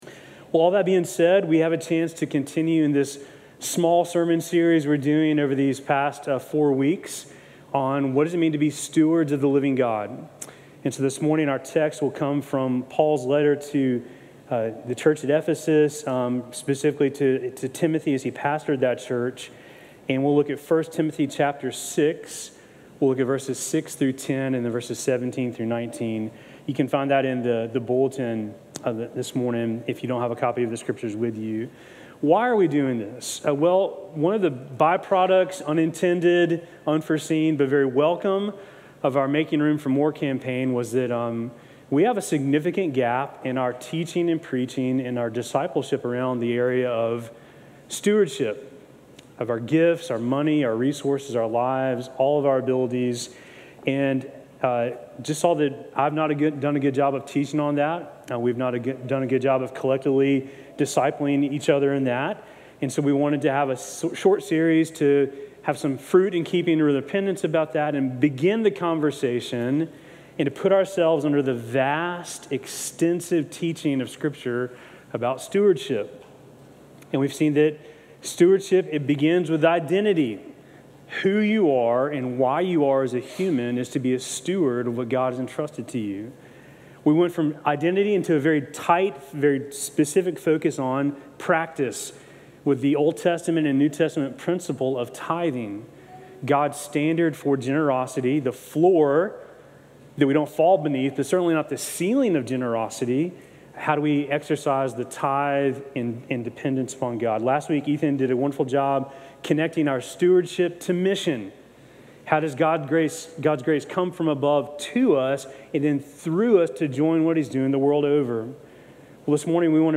Sermon from July 13